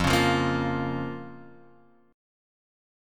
F Suspended 4th Sharp 5th